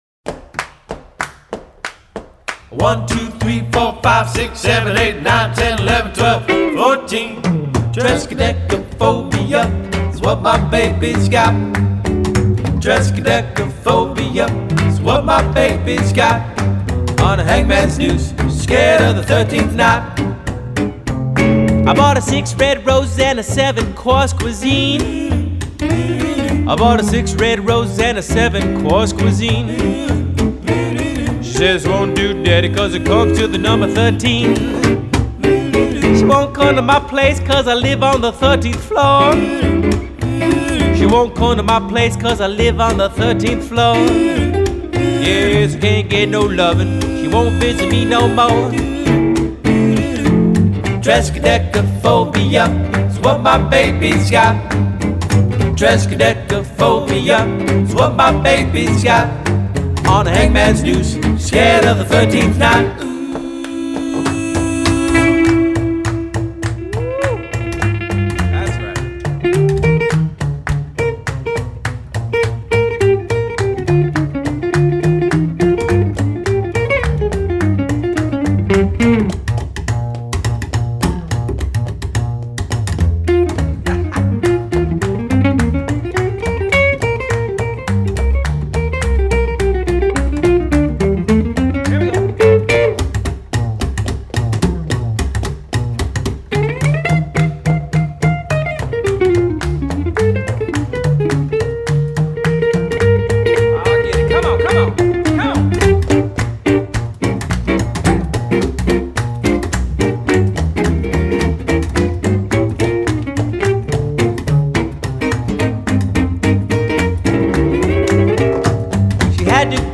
rockabilly/jazz fusion band